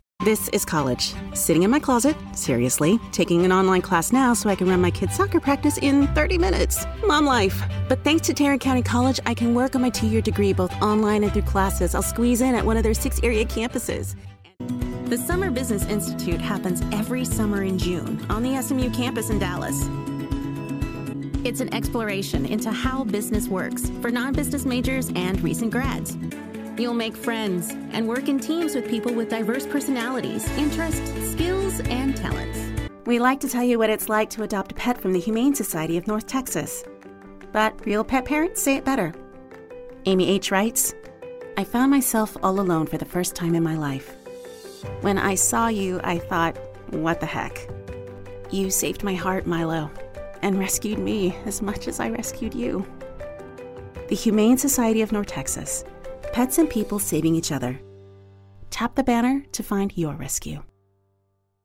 Bold and cinematic in their expression with a knack for comedic timing and a voice described as earthy, vulnerable, and nuanced
Commercial